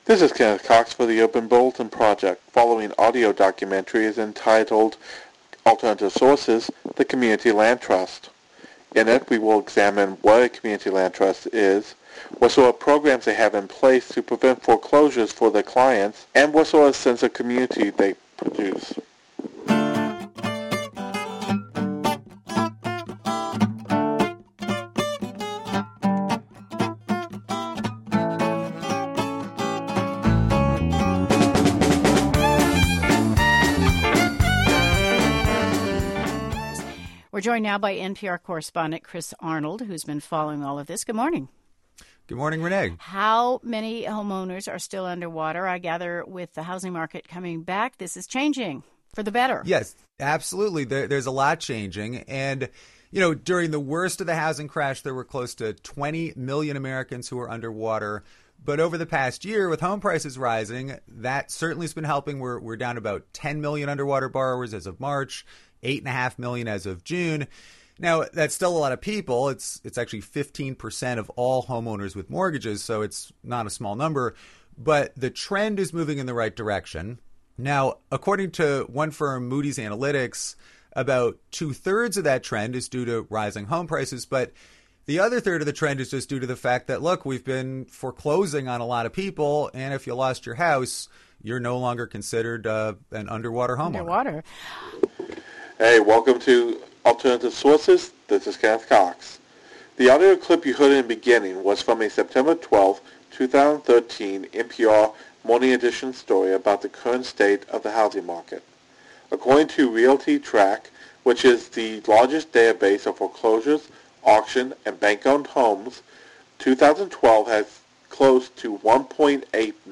The main speakers interviews others whom go into detail about the real estate market. He features those who have experience with the Seattle real estate market and what programs are in place to prevent foreclosure for any clients. Those interviewed describe what they have learned and give advice for those who want a loan from banks in order to buy a home.